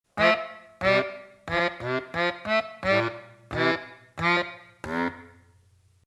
鈴木楽器製作所の鍵盤ハーモニカ「バスメロディオン B-24C」です。
練習は５分もしていないので演奏の腕は考えないことにしておいて、B-24Cは普通の鍵盤ハーモニカと比べると音の立ち上がりが遅く、強く吹き込まないとはっきり鳴ってくれません。
音域はF〜e1ということで、弦楽器のベースより１オクターブ上の音が鳴ります。